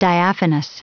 Prononciation du mot diaphanous en anglais (fichier audio)
Prononciation du mot : diaphanous